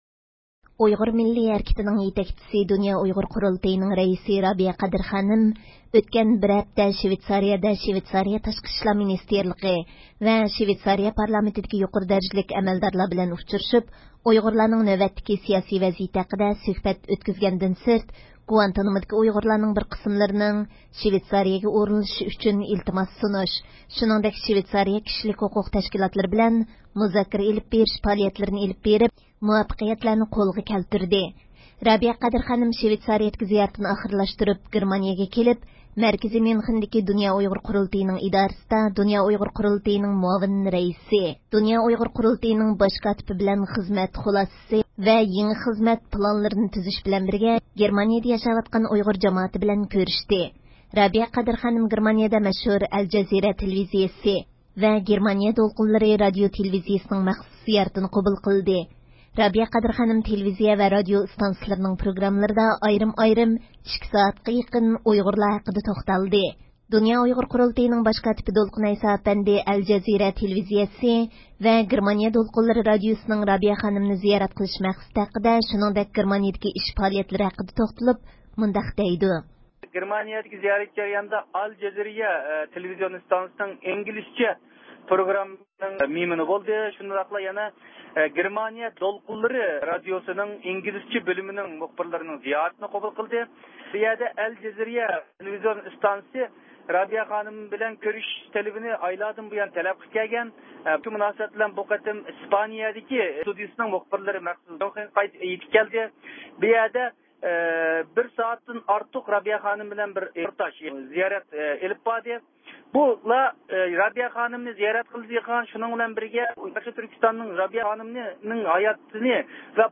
يۇقىرىدىكى ئاۋاز ئۇلىنىشىدىن، رابىيە قادىر خانىمنىڭ مەزكۇر پائالىيەتلىرى توغرىسىدا دۇنيا ئۇيغۇر قۇرۇلتىيىنىڭ باش كاتىپى دولقۇن ئەيسا ئەپەندى بىلەن ئۆتكۈزگەن سۆھبىتىمىزنىڭ تەپسىلاتىنى ئاڭلايسىلەر.